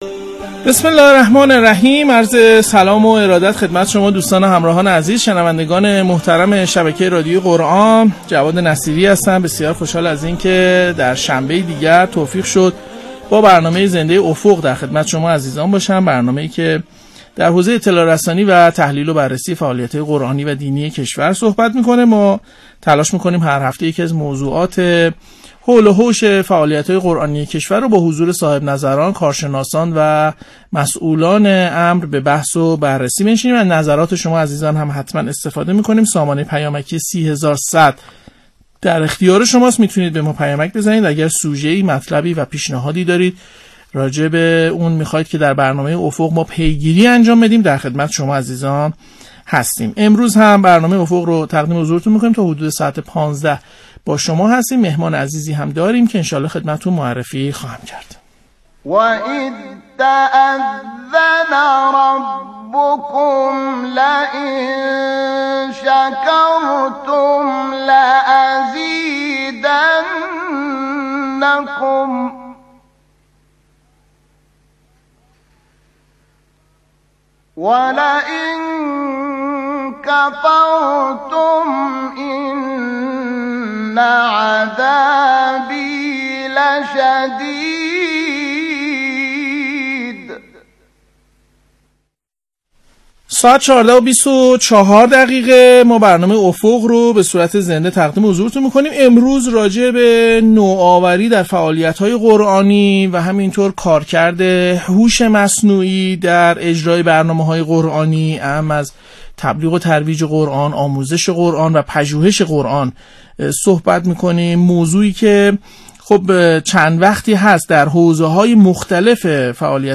در برنامه افق رادیو قرآن بیان شد